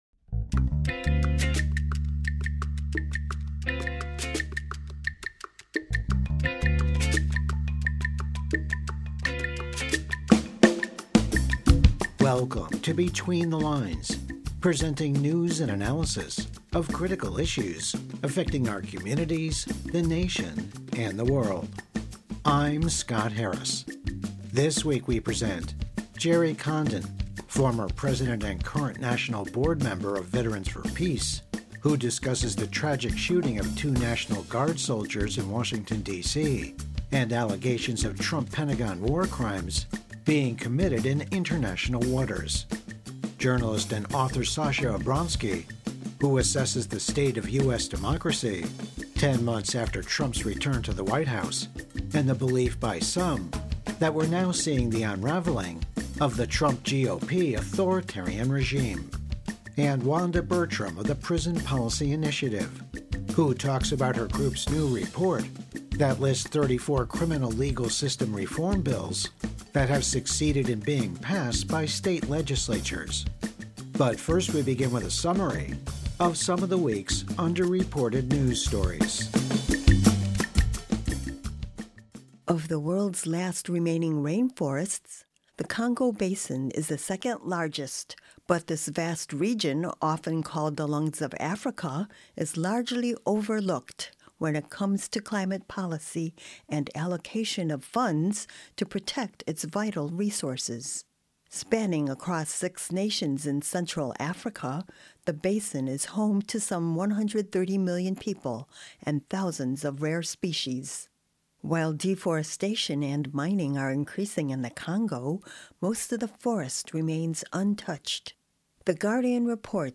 Program Type: Weekly Program